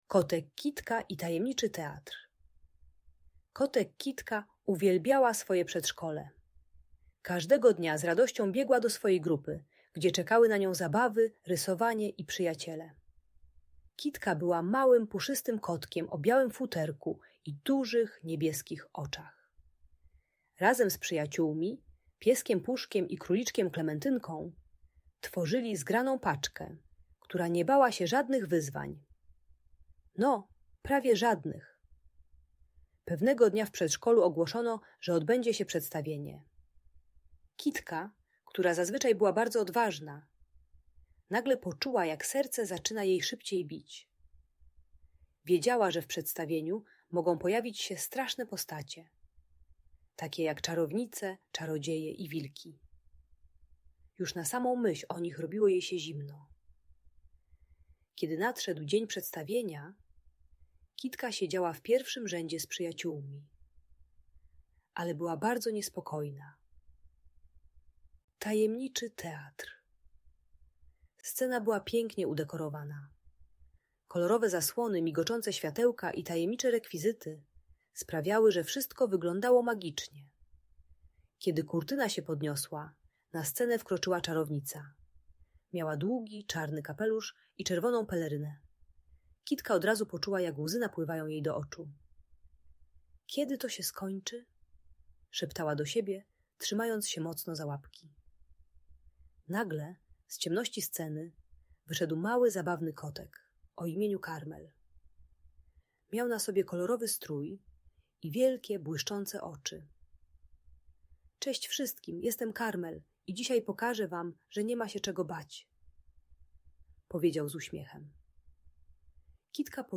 Kotek Kitka i Tajemniczy Teatr - Lęk wycofanie | Audiobajka